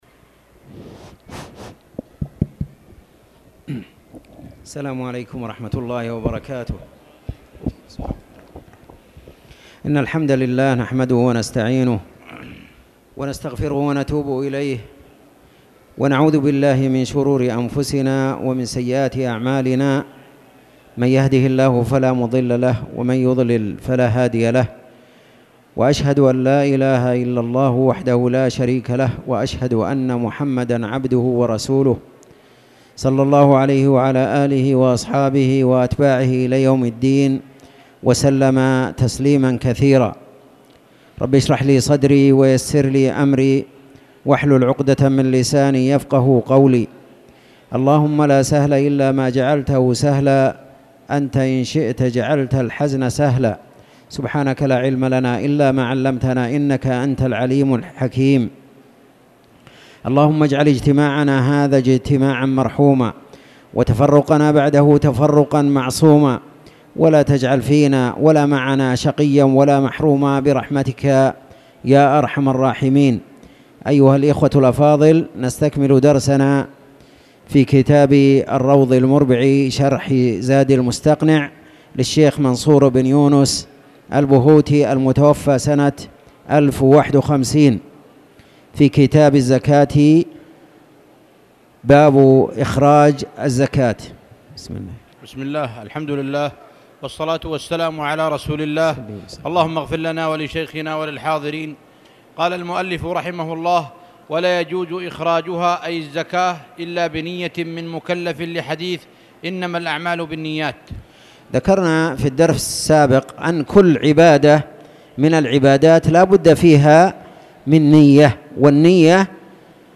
تاريخ النشر ٧ صفر ١٤٣٨ هـ المكان: المسجد الحرام الشيخ